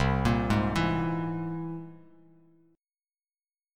Listen to C+7 strummed